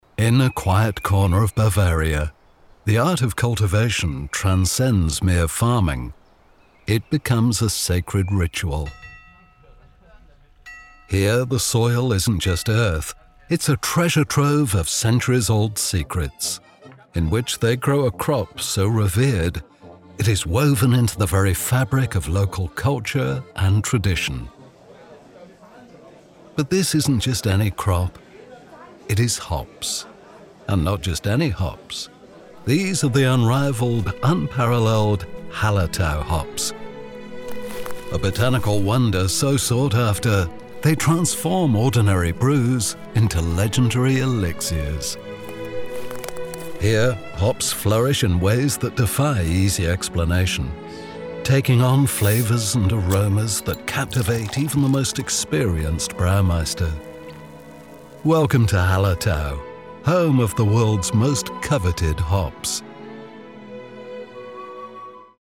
Anglais (Britannique)
Commerciale, Profonde, Distinctive, Enjouée, Chaude
Guide audio